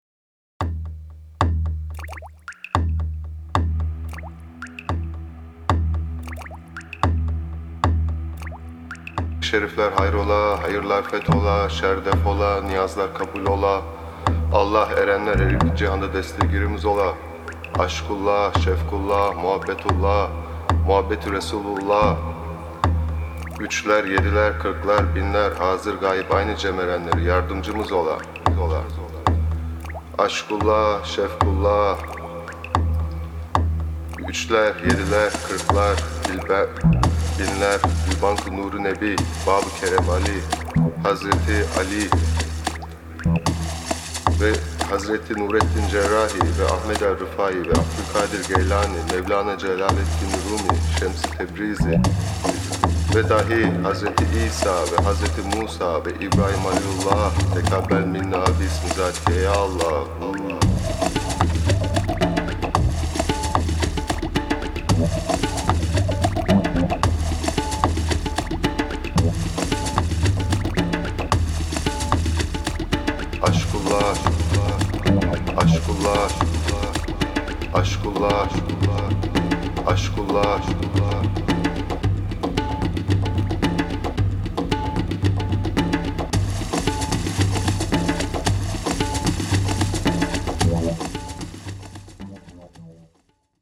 Genre: World Fusion.